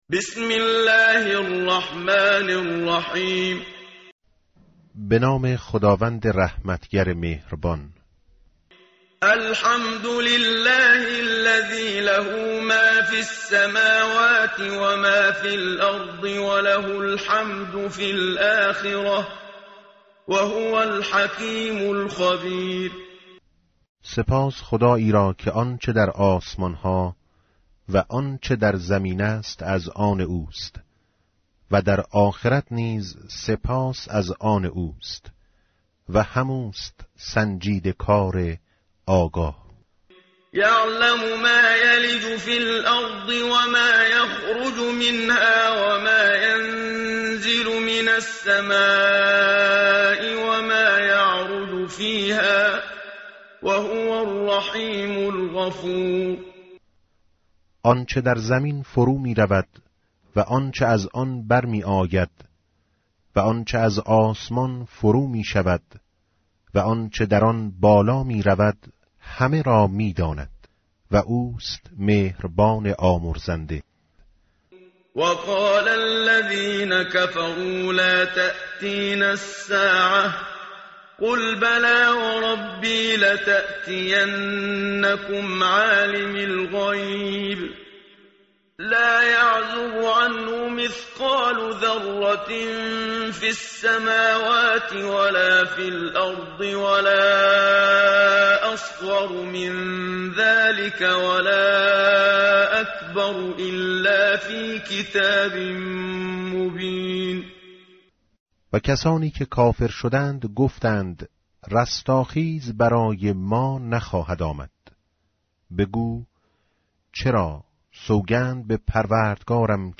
tartil_menshavi va tarjome_Page_428.mp3